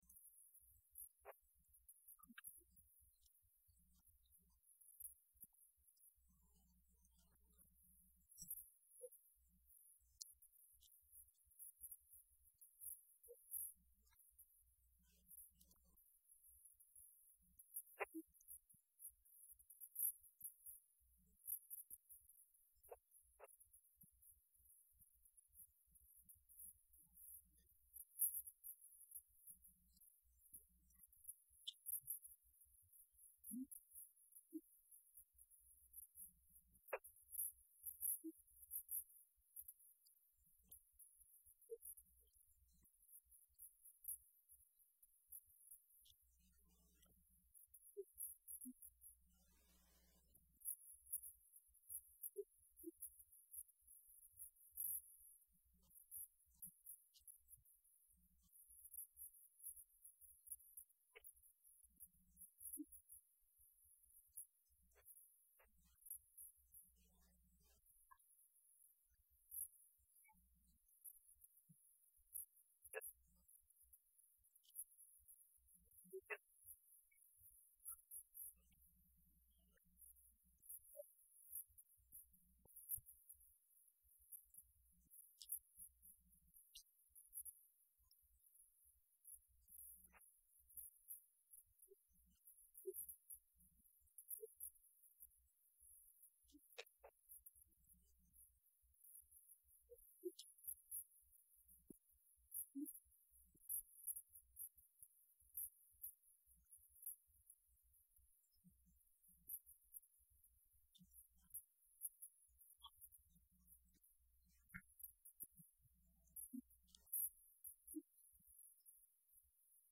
Message from 1 Timothy 1:8-11 & 18-20 addressing the proper use of the law and the danger of shipwrecking your faith.